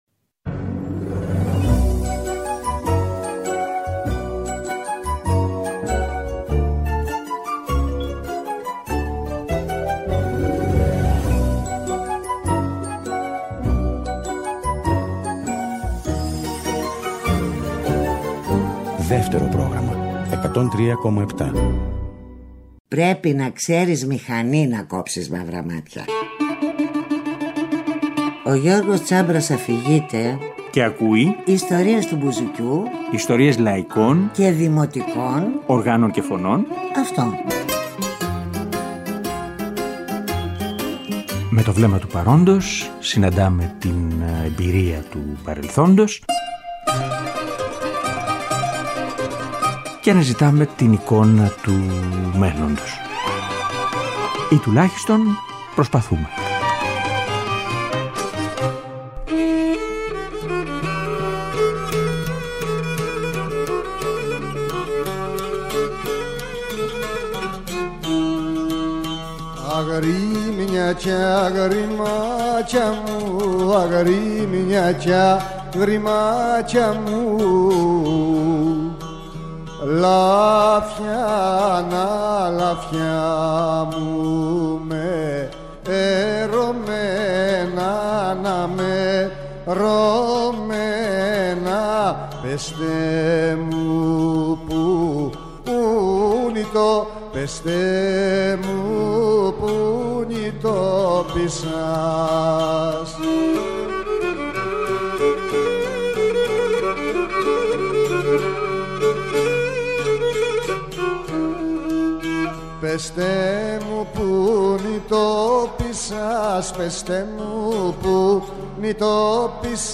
Τετάρτη 7 και Πέμπτη 8 Φεβρουαρίου, 9 με 10 το βράδυ, στο Δεύτερο Πρόγραμμα 103.7
Στις 15 και στις 29 Μαρτίου του 1979, ο Νίκος Ξυλούρης βρίσκεται στα στούντιο της Ελληνικής Ραδιοφωνίας για να παίξει με τη λύρα του και να τραγουδήσει ζωντανά, μια σειρά από τραγούδια και οργανικά της Κρήτης.